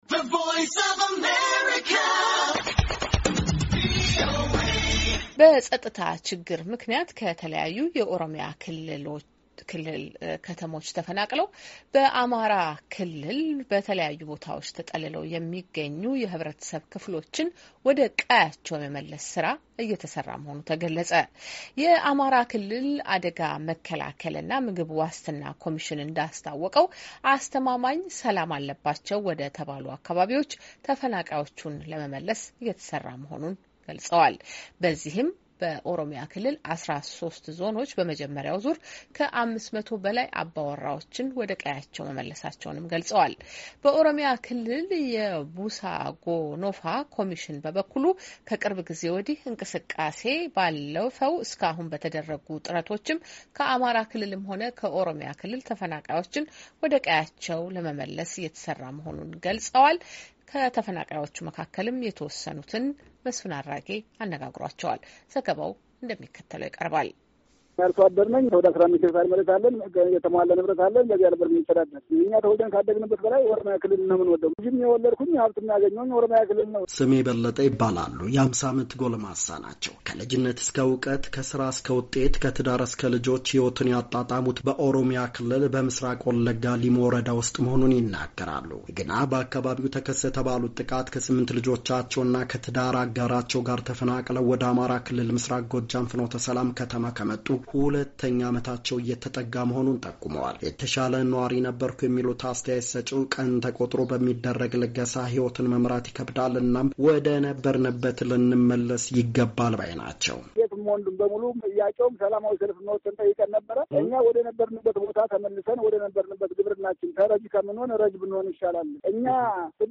የአሜሪካ ድምፅ ያነጋገራቸው ሁለት ተፈናቃዮች ወደ ቀያቸው እንዲመለሱ መደረጉ እንዳስደሰታቸው ገልፀዋል።